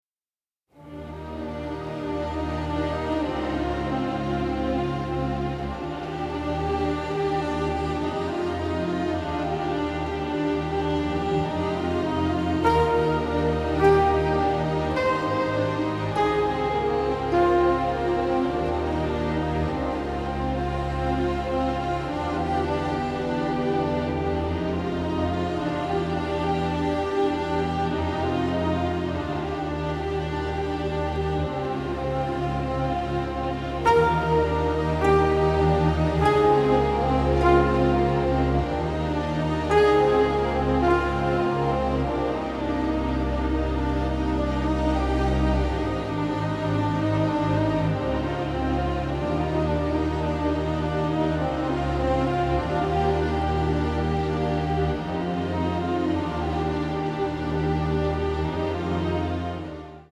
romantic, innovative, masterful score